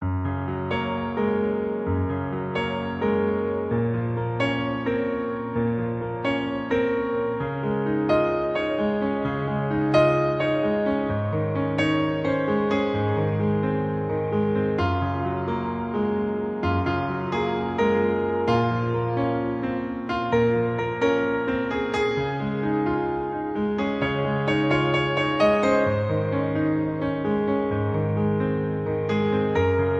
• Key: F# Major
• Instruments: Piano solo
• Genre: Pop, Film/TV